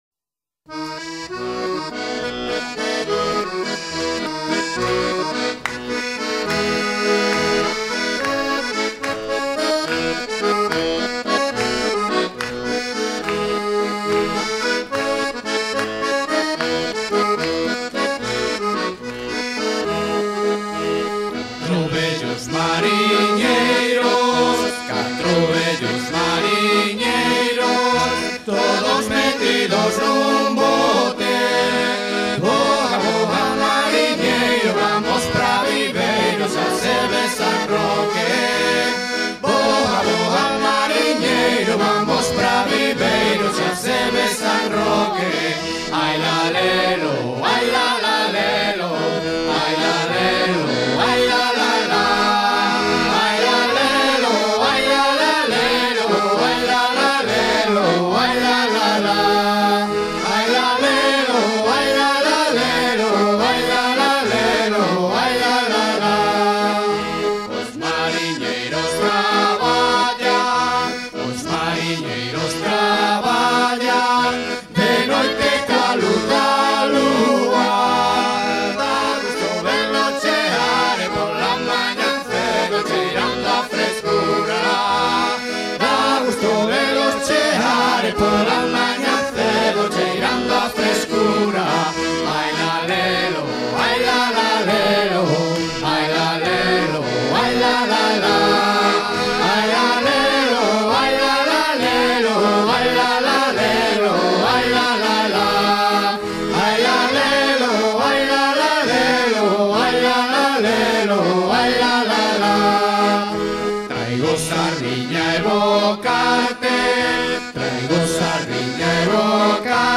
Galice
danse : valse
Genre strophique